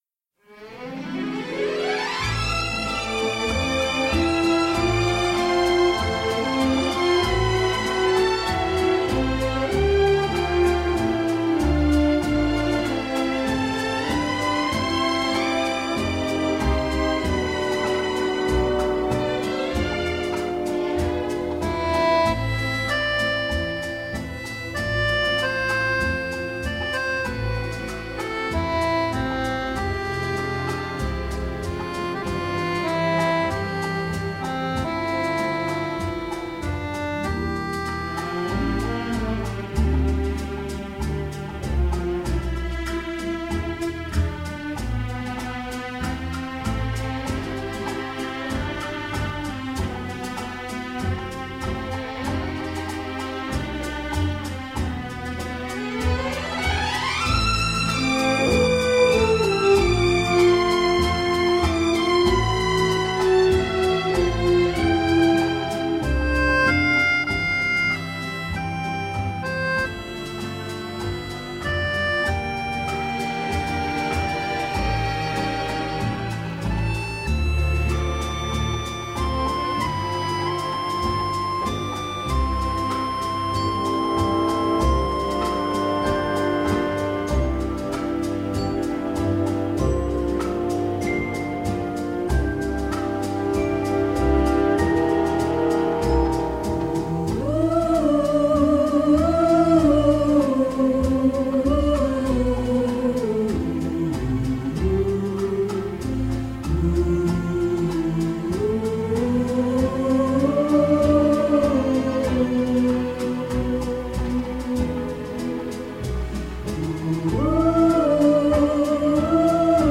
La romance se teinte aussi d’un genre de suspense trouble.
Du velours pour les oreilles.